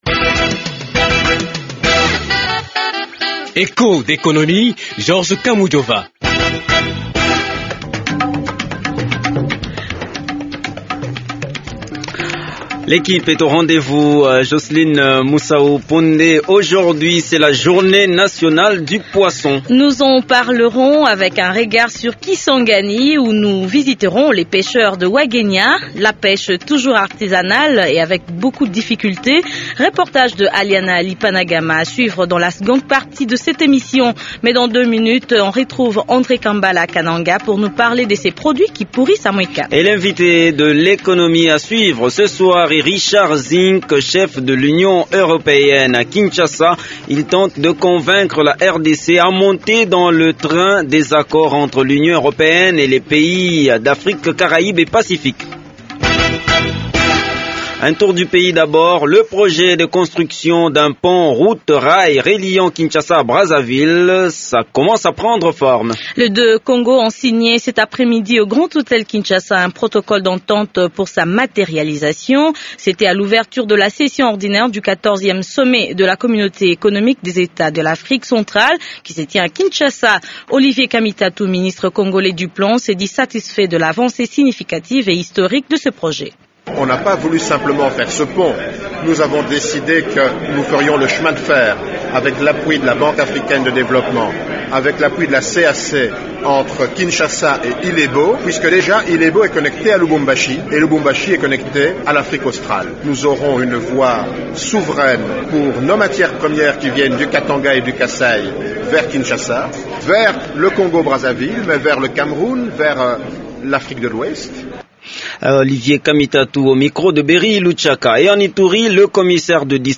Le chef de la commission européenne à Kinshasa est l’invité de l’Economie. Richard Zink tente de convaincre la RDC à monter dans le train des accords entre l’Union Européenne et les pays Afrique-Caraïbes et Pacifique. Regard sur les pêcheurs de Wagenia, à Kisangani, à l’occasion de la journée nationale du poisson. A Mweka, ce sont les produits agricoles qui pourrissent alors que Kananga en a besoin.